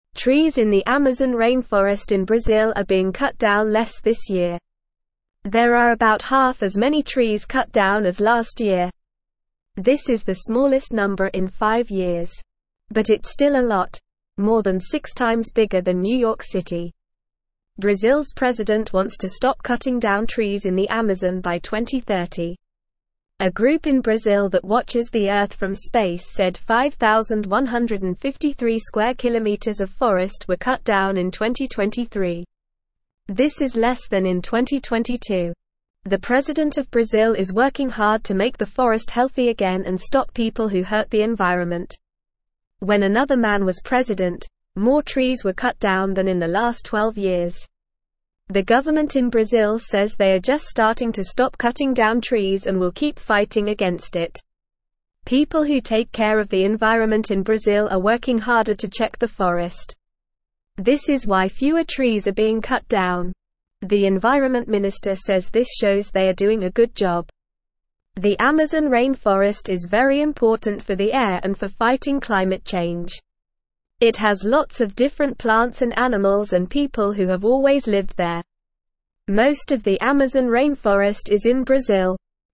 Normal
English-Newsroom-Lower-Intermediate-NORMAL-Reading-Brazils-Forest-Fewer-Trees-Cut-Down-But-Still-Too-Many.mp3